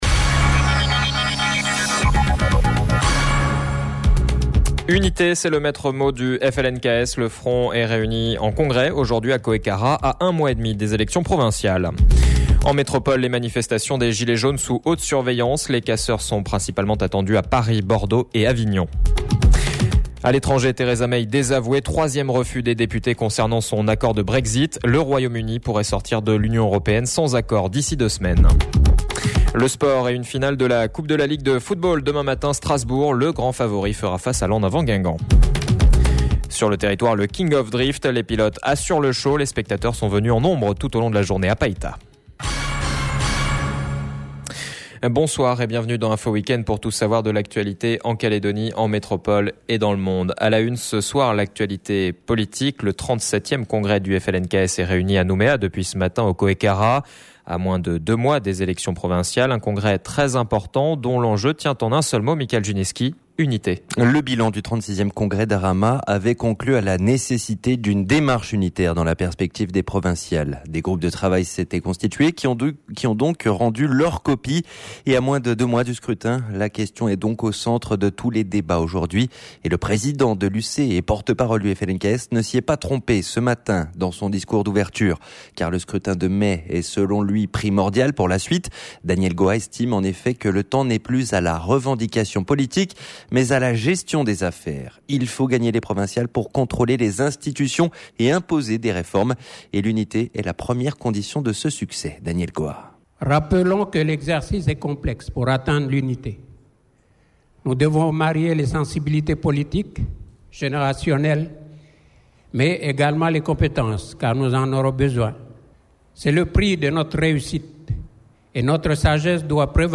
JOURNAL SAMEDI 30/03/19 (SOIR)